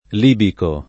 libico [ l & biko ]